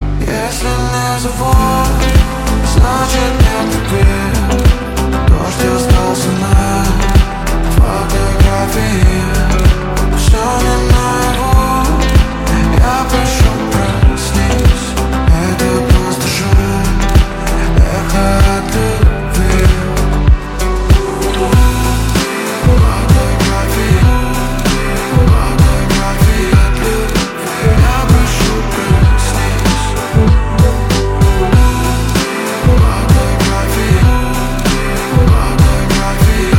рэп , рок
поп , рэп-рок , дуэт